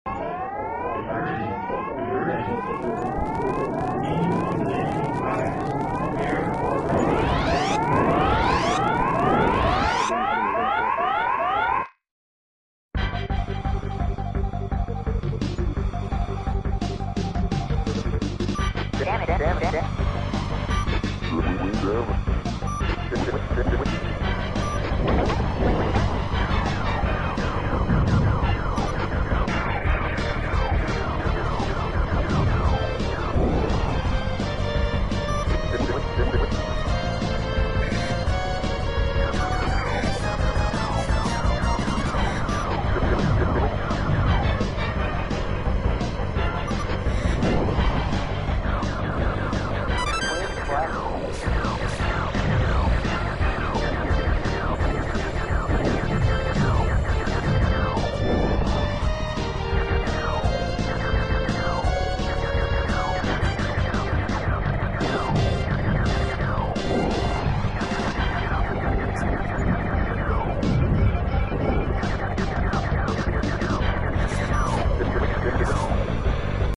Starwing (1993) – The SNES sound effects free download